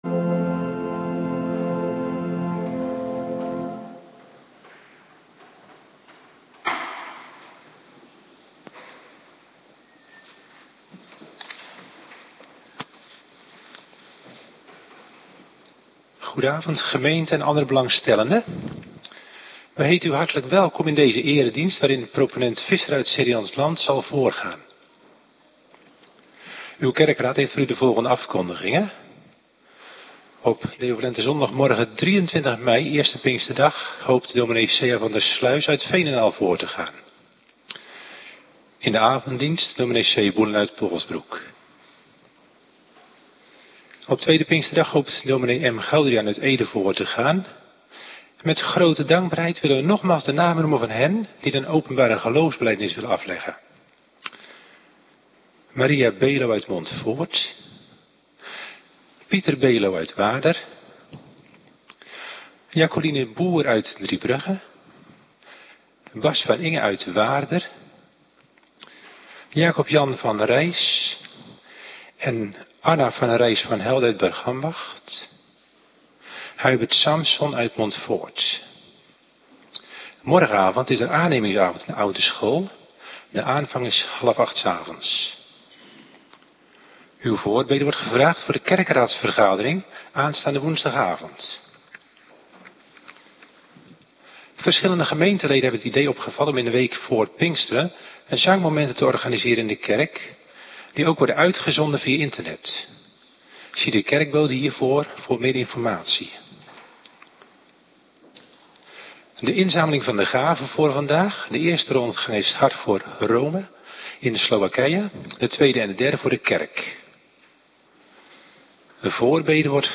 Avonddienst - Cluster 2
Locatie: Hervormde Gemeente Waarder